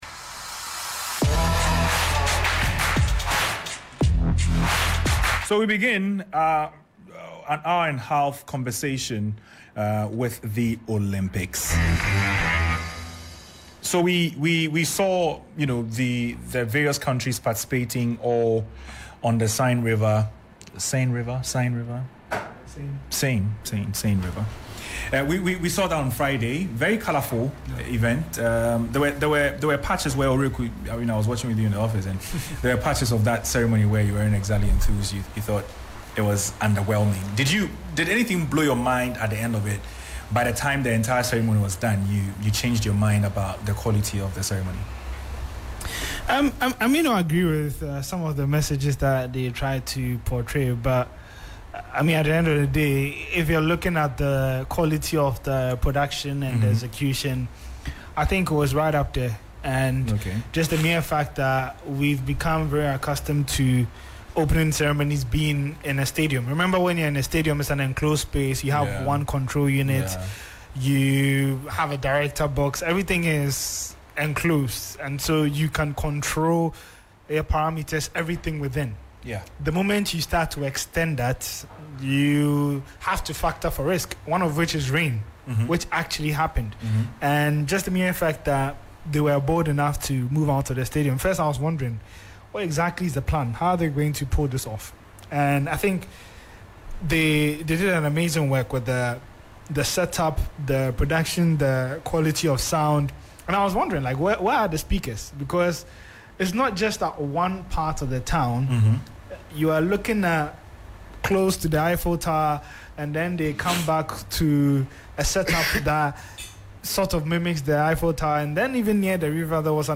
The 3sports crew discuss the 2024 Olympics and also look into the Ghanaian team at the tournament.